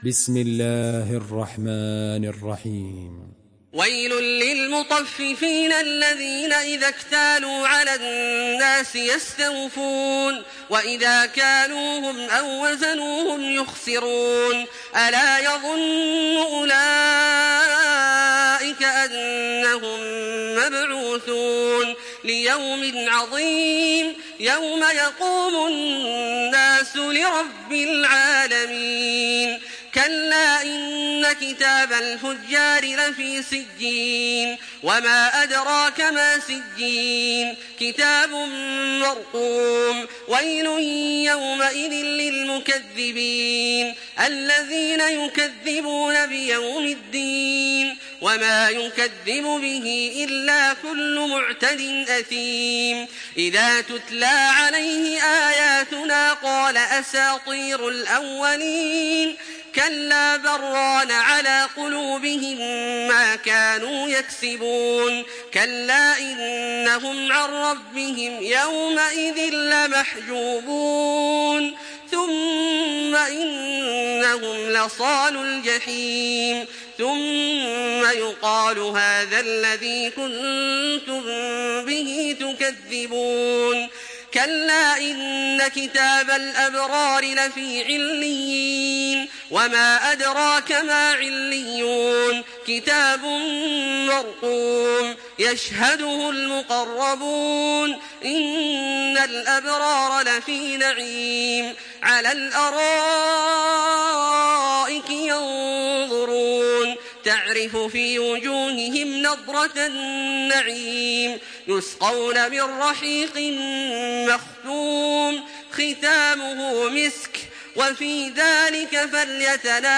Surah Müteffifin MP3 by Makkah Taraweeh 1426 in Hafs An Asim narration.
Murattal